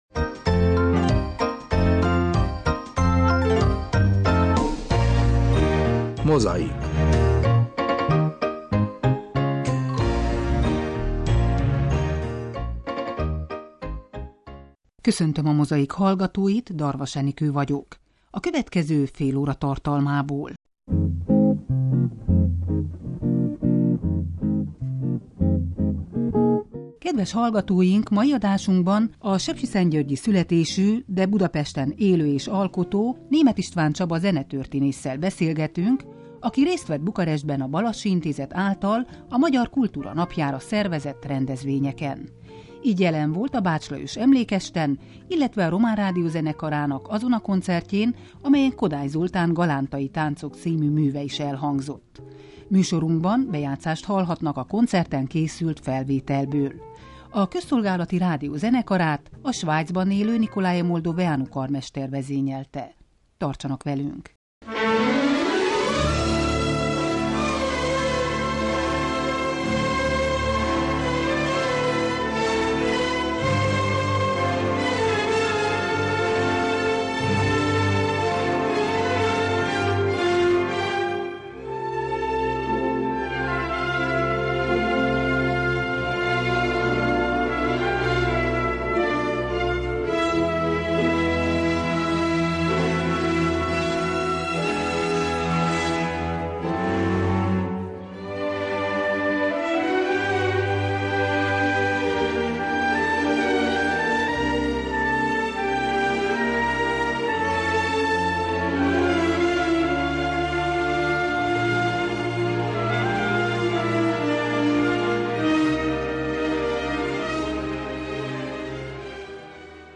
Adásunkat Kodály Zoltán Galántai táncok című szerzeményével illusztráljuk.